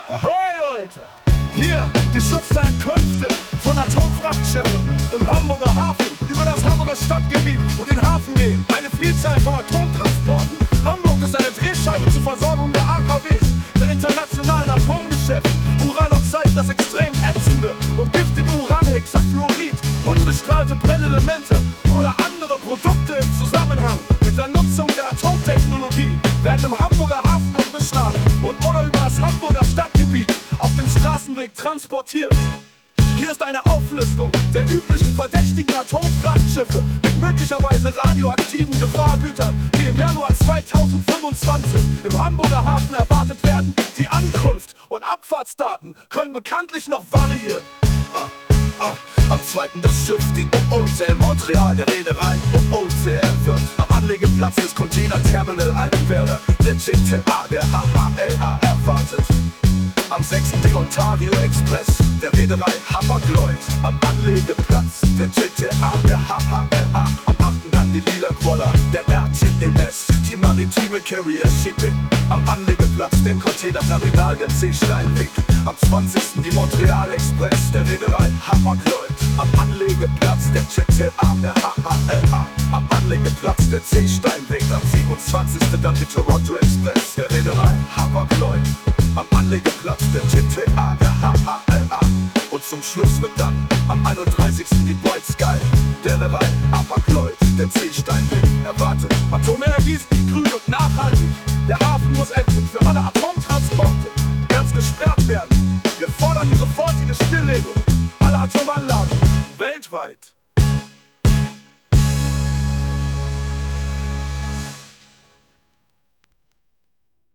Schiffsankuenfte-Hip-hop-funk2.mp3